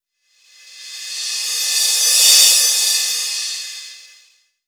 Crashes & Cymbals
Cre_Crsh.wav